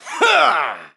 One of Ludwig's voice clips in New Super Mario Bros. Wii